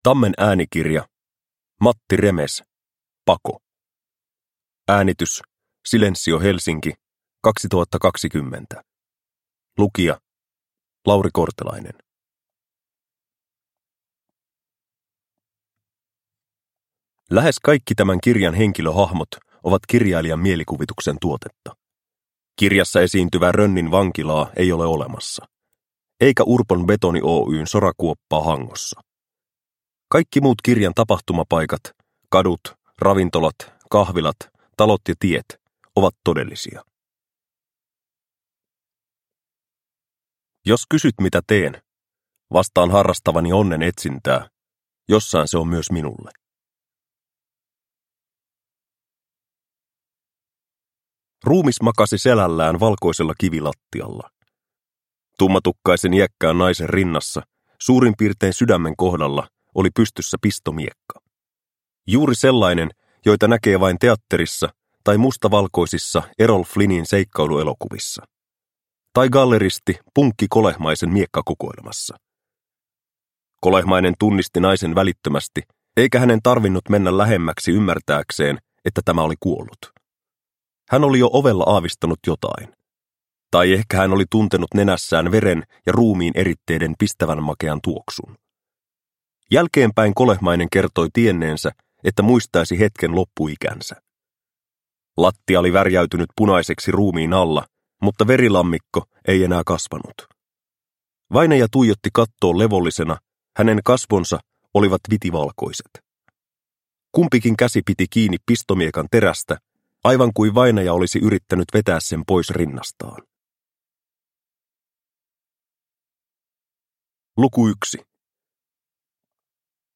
Pako – Ljudbok – Laddas ner